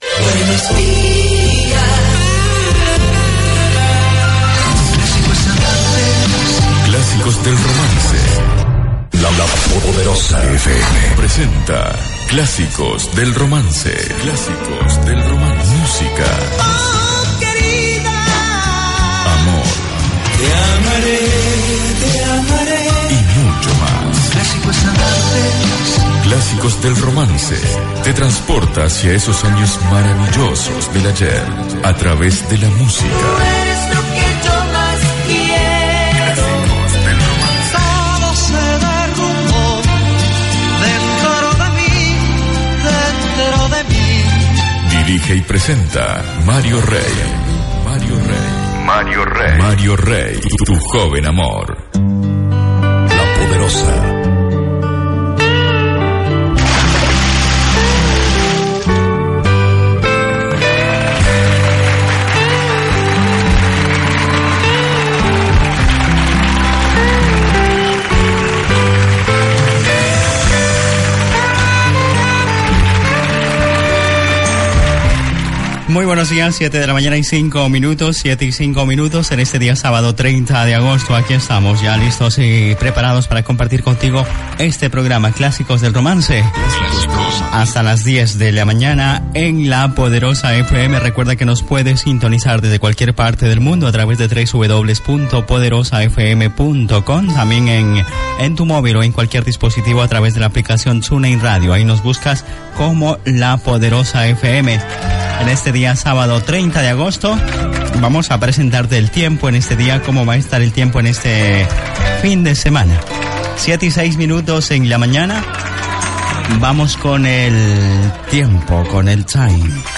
Careta del programa, hora, identificació, formes d'escoltar el programa, hora, l'estat del temps, telèfon de contacte i xarxes socials del programa i tema musical
Musical